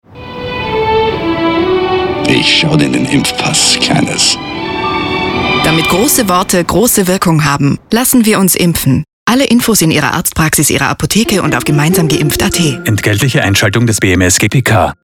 Hörfunk Spots